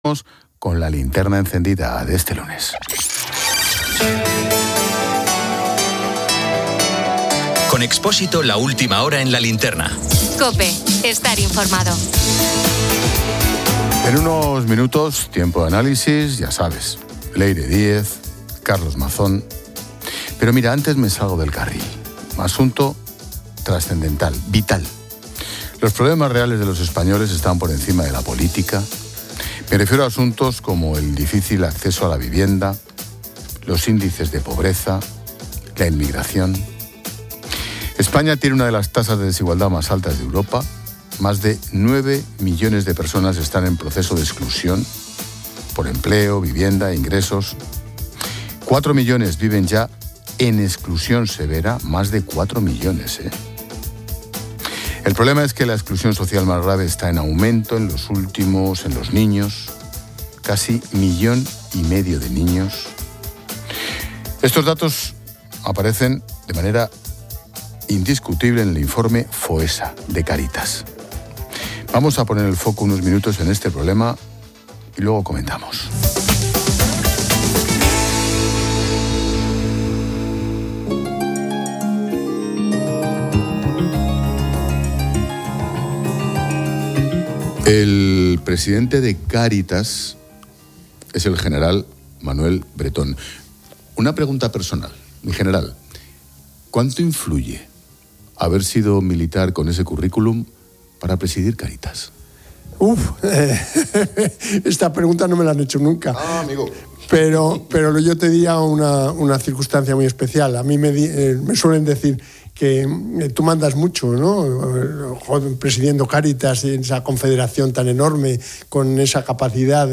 La entrevista se cierra con un llamado a la confianza, la fe, la esperanza y la dignidad.